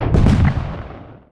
EXPLOSION_Arcade_20_mono.wav